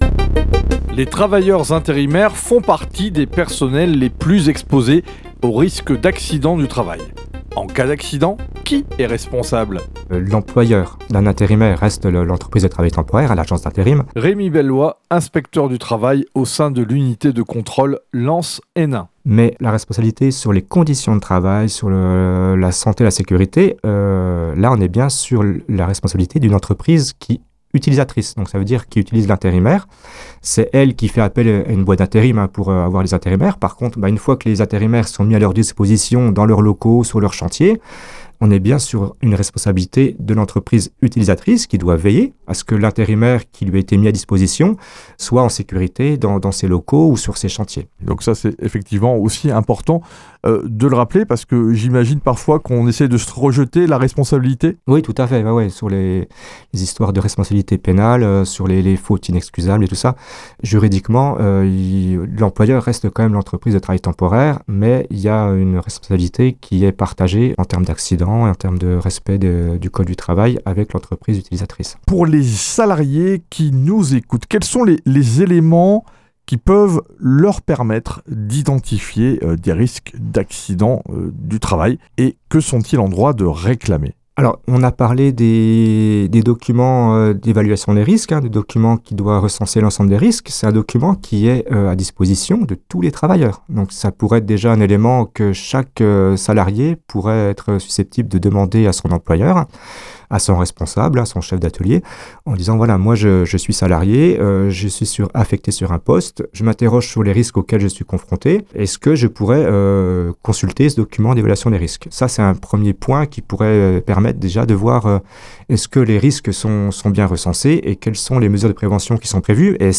8 spots radio diffusés à l’automne 2025 :